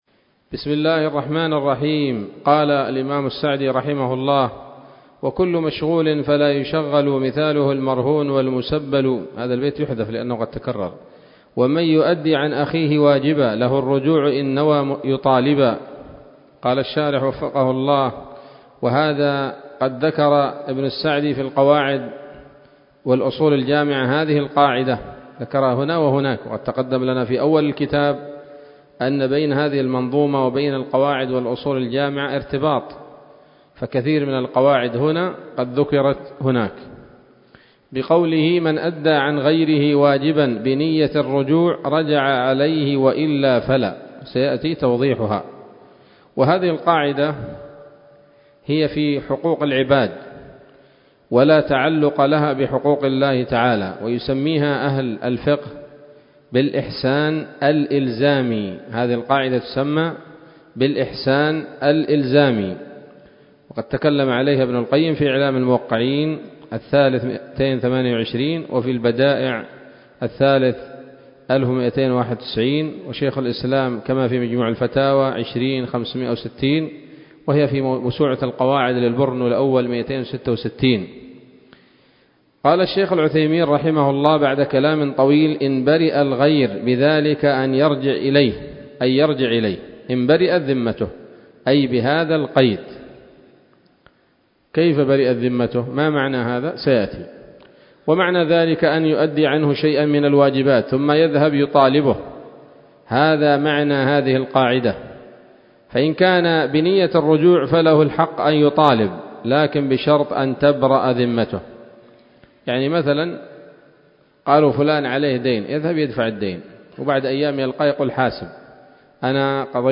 الدرس الخمسون والأخير من الحلل البهية في شرح منظومة القواعد الفقهية